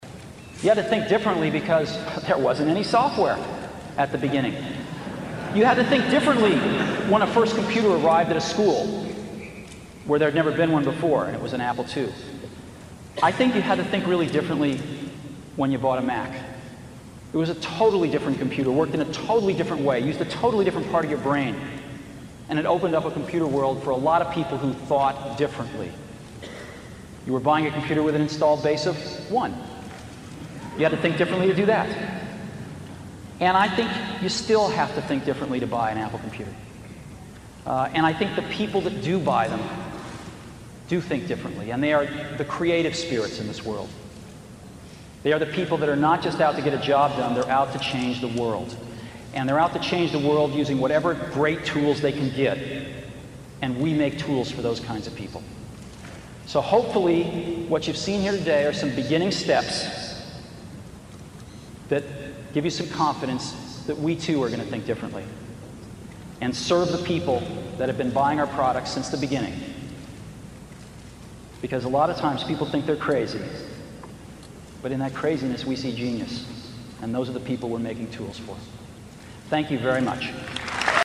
财富精英励志演讲94:在疯狂中我们看到了天才(14) 听力文件下载—在线英语听力室
这些财富精英大多是世界著名公司的CEO，在经济领域成就斐然。在演讲中他们或讲述其奋斗历程，分享其成功的经验，教人执着于梦想和追求；或阐释他们对于公司及行业前景的独到见解，给人以启迪和思考。